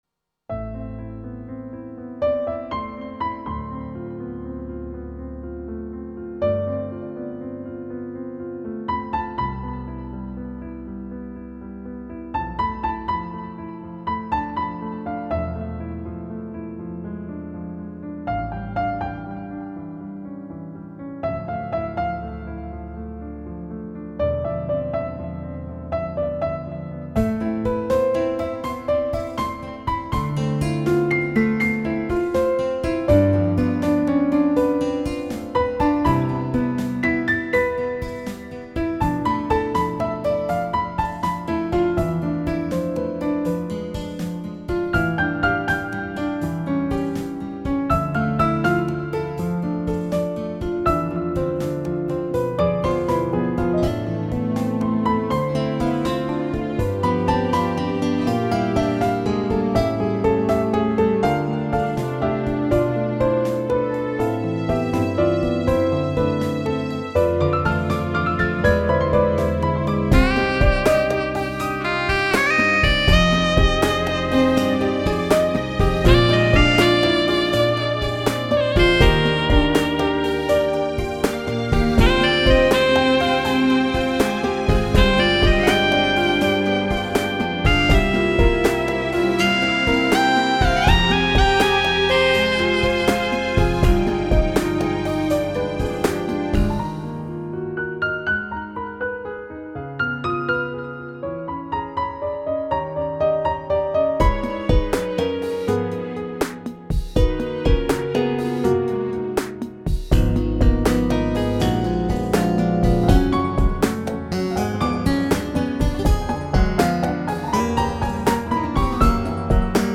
сумно!музика чудова!під мій настрій ідеально підходить!